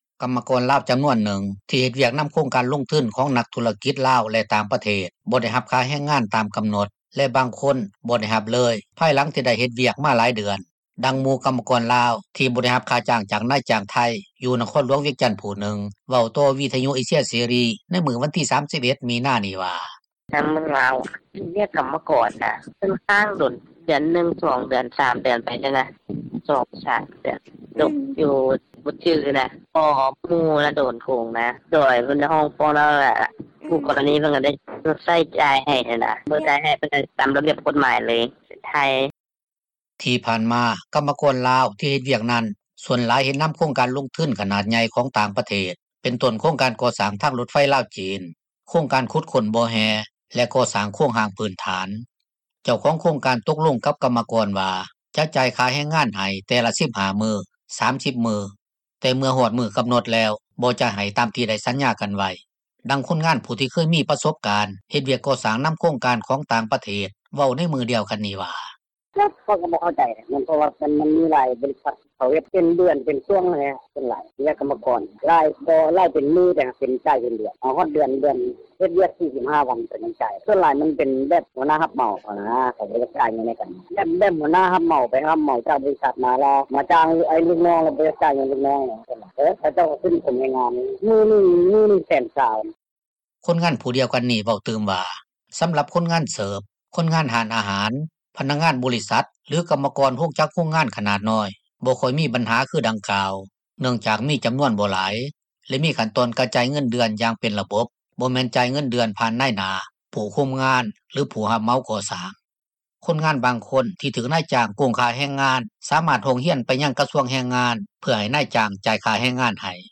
ກັມມະກອນລາວຈໍານວນນຶ່ງ ທີ່ເຮັດວຽກນໍາໂຄງການລົງທຶນ ຂອງນັກທຸຣະກິຈລາວ ແລະຕ່າງປະເທດ ບໍ່ໄດ້ຮັບຄ່າແຮງງານ ຕາມກໍານົດ ແລະບາງຄົນບໍ່ໄດ້ຮັບເລີຍ ພາຍຫລັງທີ່ໄດ້ເຮັດວຽກ ມາຫລາຍເດືອນ ດັ່ງໝູ່ຂອງກັມມະກອນລາວ ທີ່ບໍ່ໄດ້ຄ່າຈ້າງຈາກນາຍຈ້າງໄທຍ ຢູ່ນະຄອນຫລວງວຽງຈັນຜູ້ນຶ່ງ ເວົ້າຕໍ່ວິທຍຸເອເຊັຽເສຣີໃນມື້ວັນທີ 31 ມິນານີ້ວ່າ:
ດັ່ງຄົນງານຜູ້ທີ່ເຄີຍມີປະສົບການ ເຮັດວຽກກໍ່ສ້າງນໍາໂຄງການຂອງຕ່າງ ປະເທດ ເວົ້າໃນມື້ດຽວກັນນີ້ວ່າ: